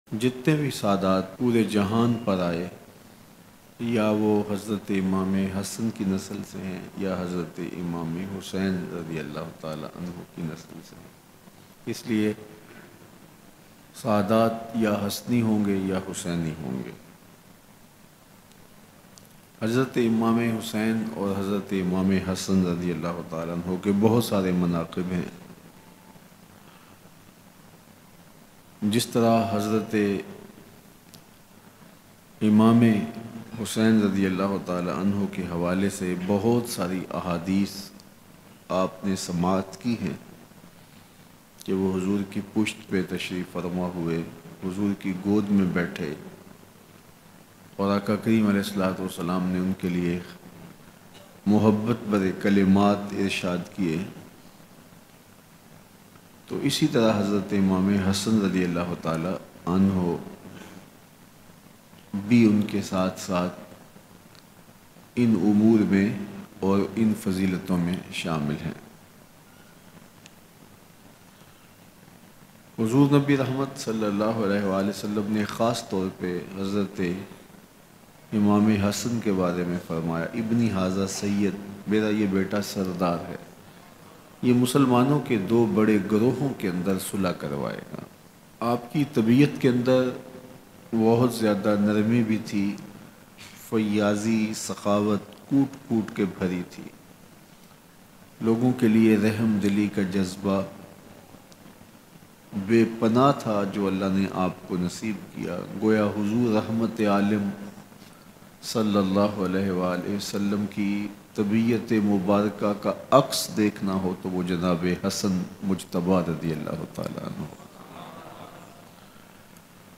Bayan